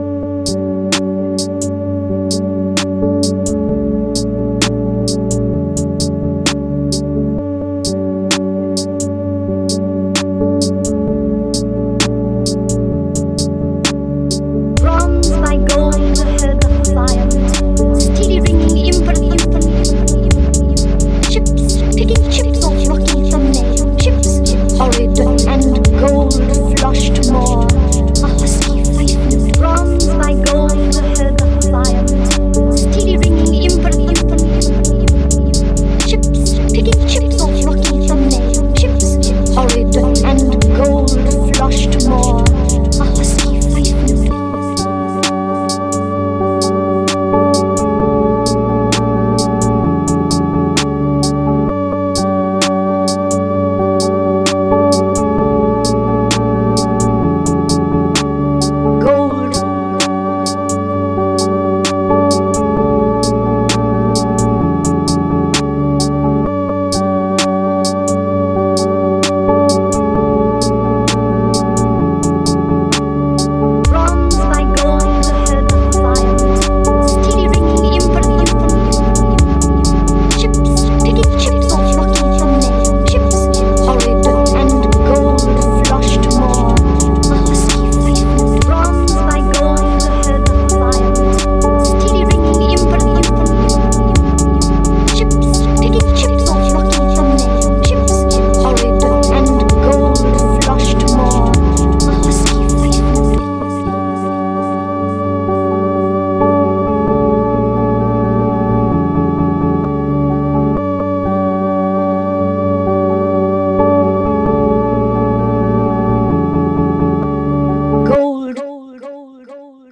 ambient, downtempo, remix,